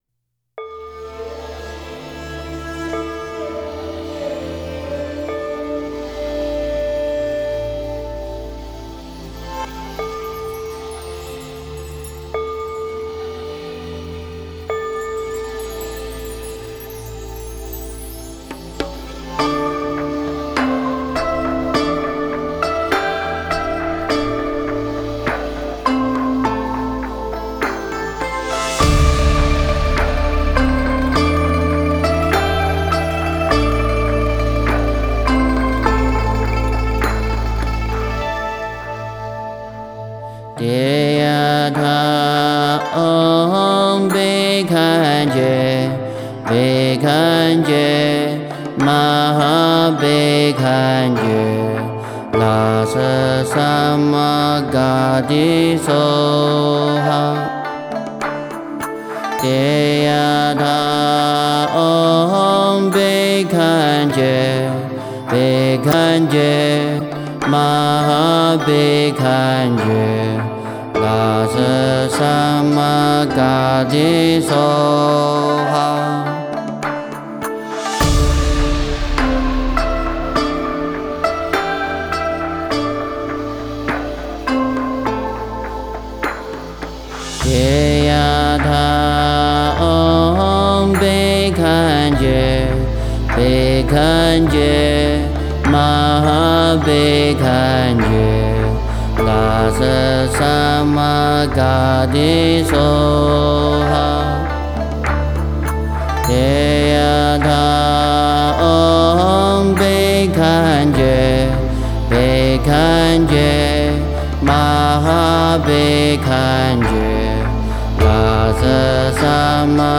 药师佛心咒.mp3